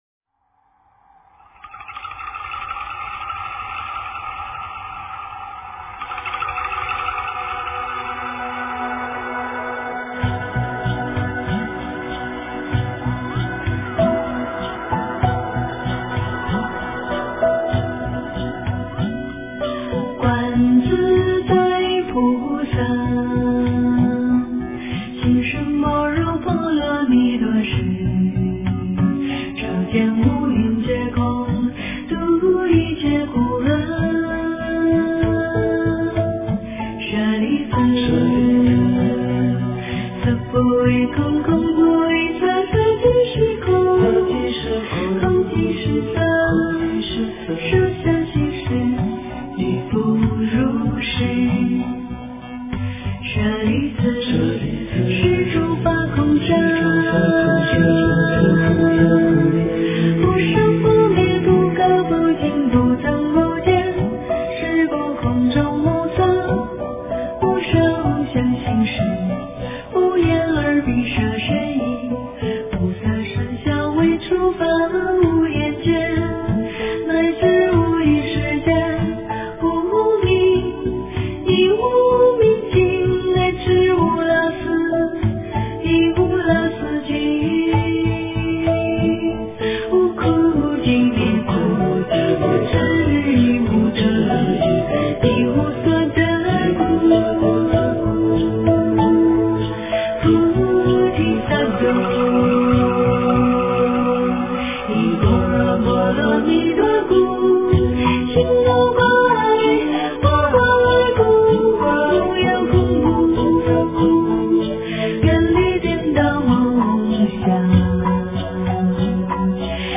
心经 诵经 心经--佚名 点我： 标签: 佛音 诵经 佛教音乐 返回列表 上一篇： 心经 下一篇： 大悲咒 相关文章 般若波罗密多心经 般若波罗密多心经--水晶梵乐...